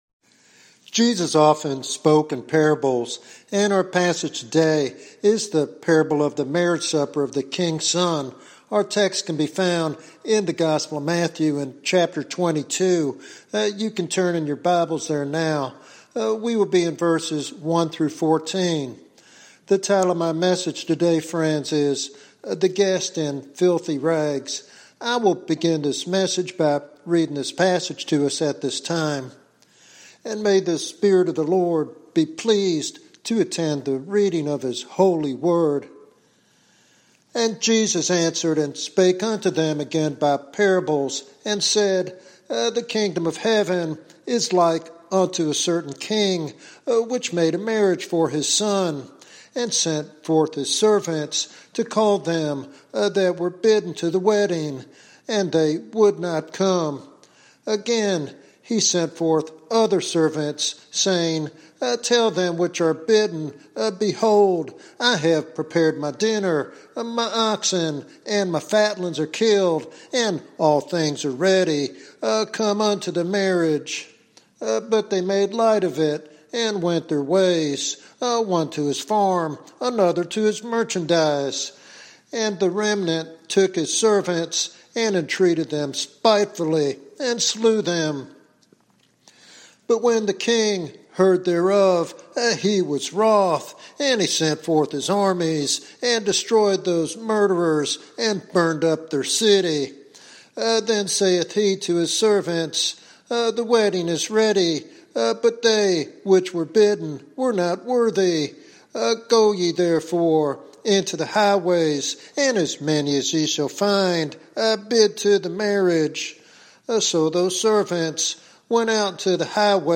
This sermon calls listeners to genuine repentance, full surrender to Christ, and a life marked by obedience and holiness.